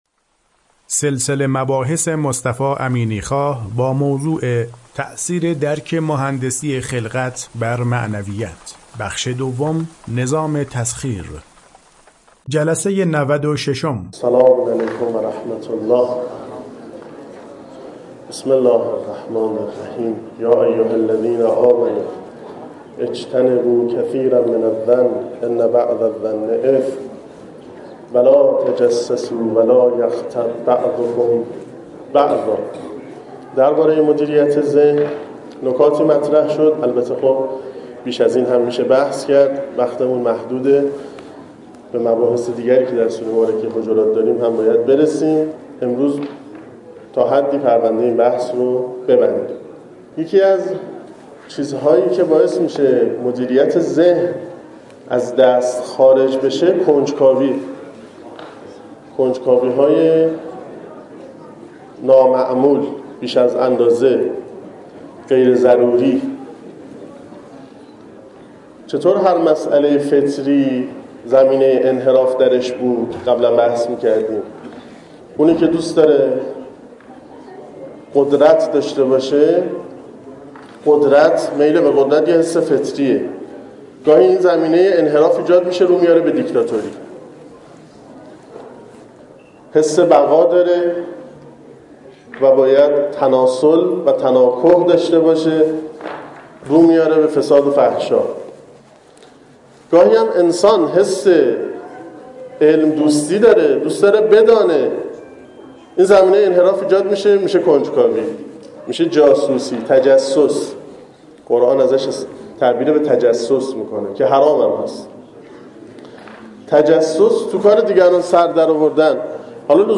سلسله مباحث مهندسی خلقت که در دانشکده مهندسی دانشگاه فردوسی ارائه شده در چند بخش پیگیری می شود که شمای کلی آن بدین شرح است:
سخنرانی